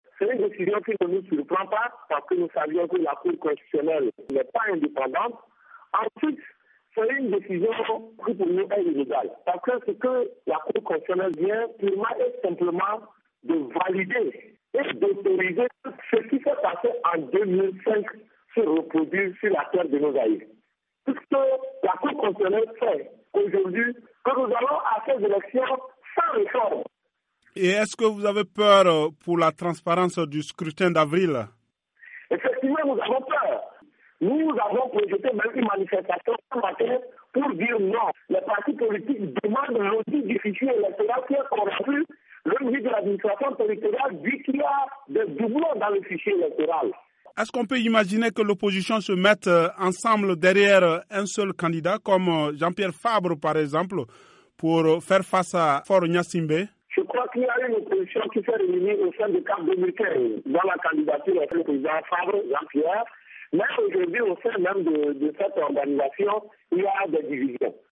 joint à Lomé